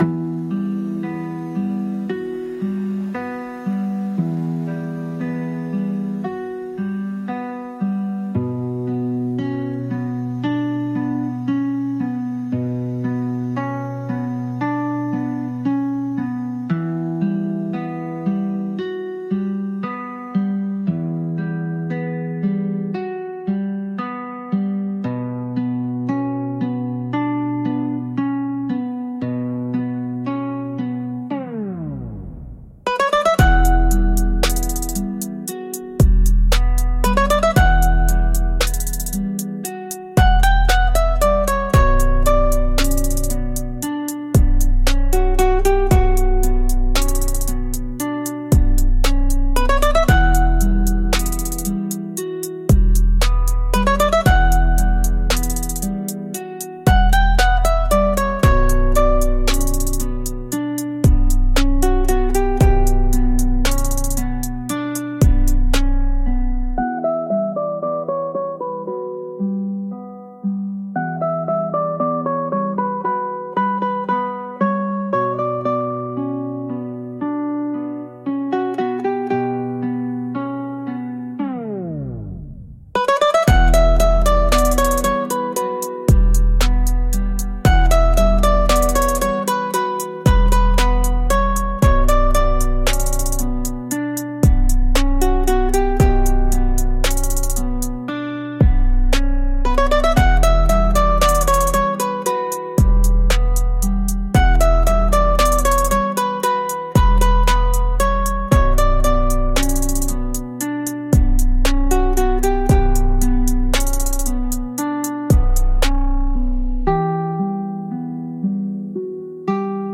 B Minor – 115 BPM
Electronic
Pop
Trap